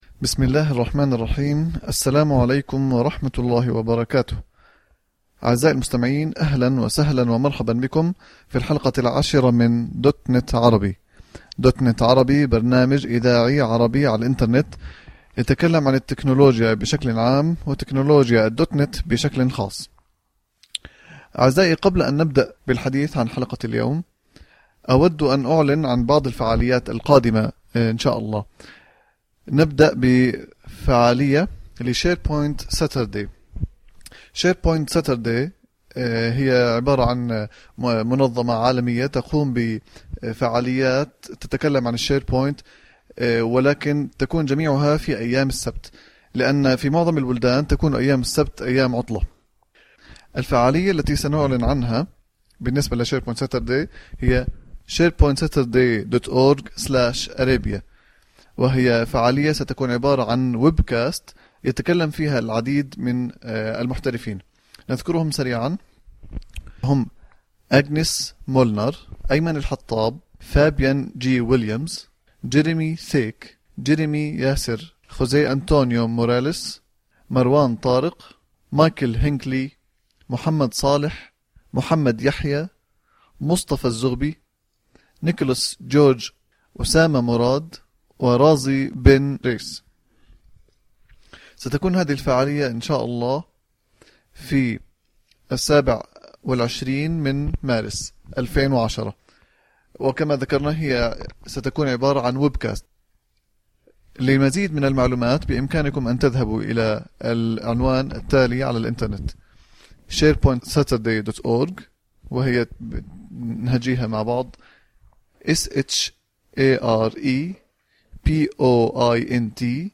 الحلقة 10 عبارة عن تغطية لفعالية “شيربوينت ساترداي جوردان” التي حدثت في الأردن في 17/2/2010.
لكن تم إلحاق اللقائين الأصليين بالإنجليزية بآخر الحلقة.